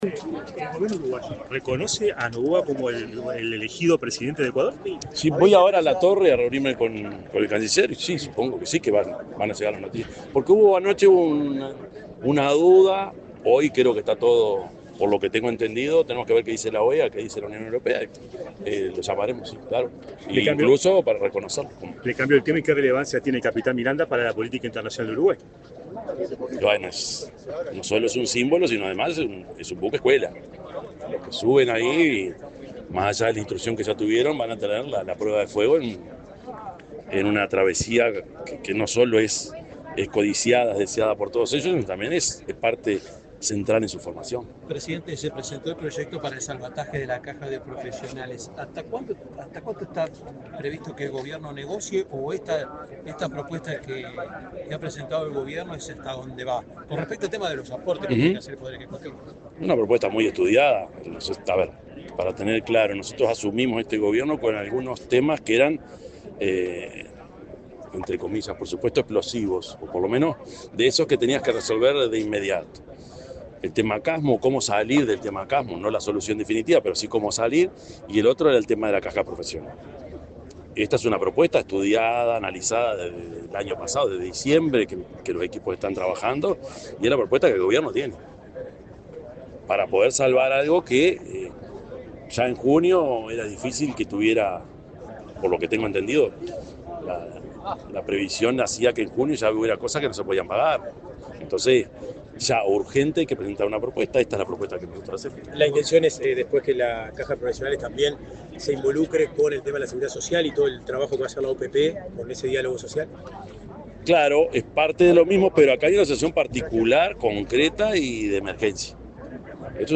Declaraciones del presidente Yamandú Orsi 14/04/2025 Compartir Facebook X Copiar enlace WhatsApp LinkedIn Este lunes 14 en el puerto de Montevideo, el presidente de la República, Yamandú Orsi, dialogó con la prensa, luego de participar en la ceremonia con motivo de la salida del buque escuela Capitán Miranda, cuyo XXXV viaje de instrucción comienza en la jornada.